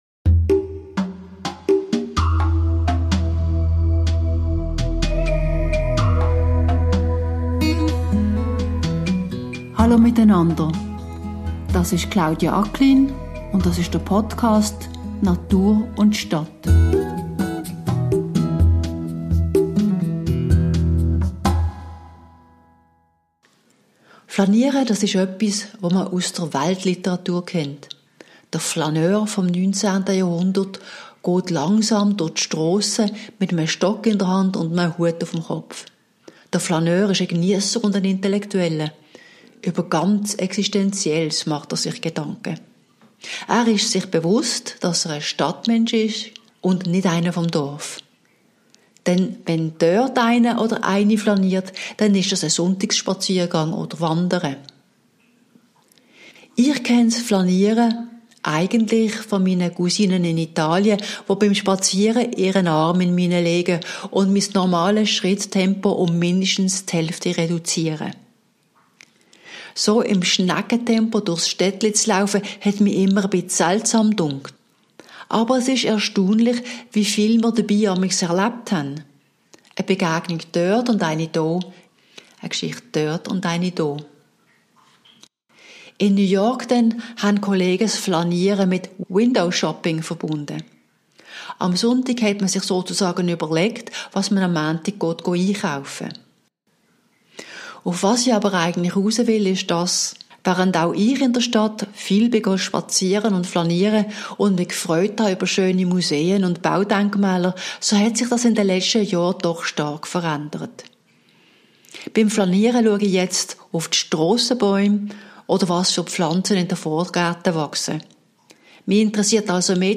liest Blog-Beiträge, die sich mit einer neuen Sicht auf die Stadtnatur beschäftigen